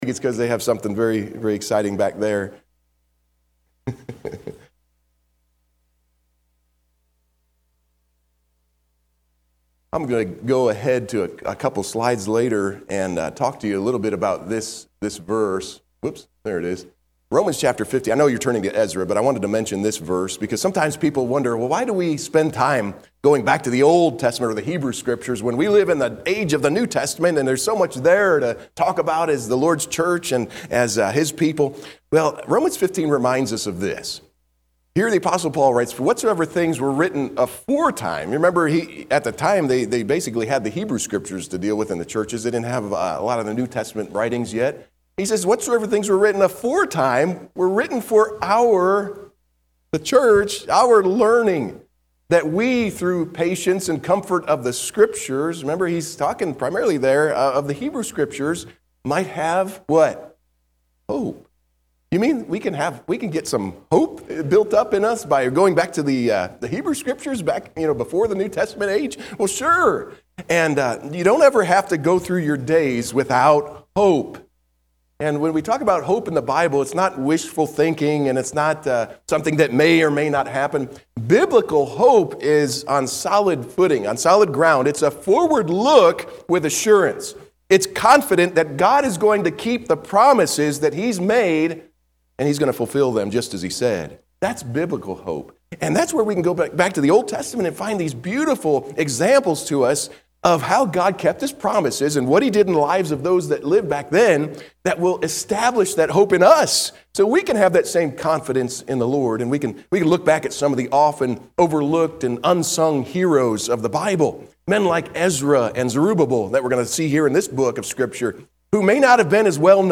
Sunday, March 16, 2025 – AM Service, “What are we Waiting for?”